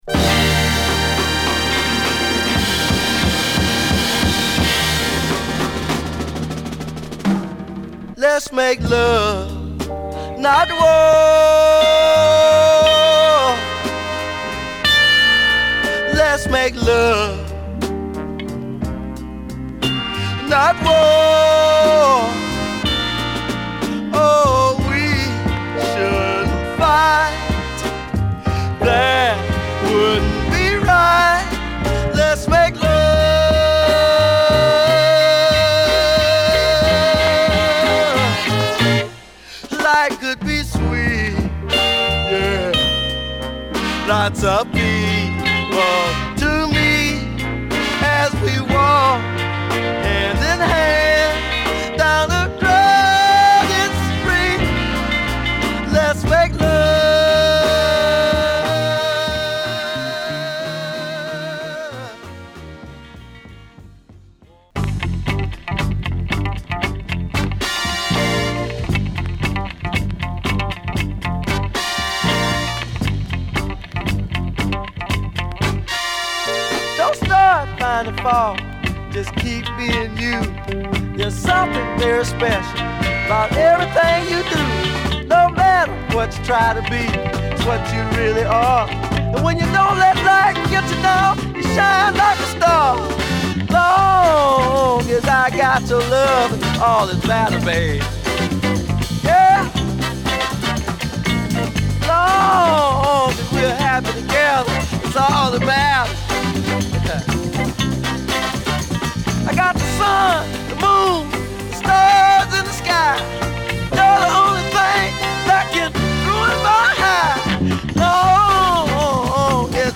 7inch
R&Bバラードなバックに下手ウマな味のありまくるヴォーカルが乗るA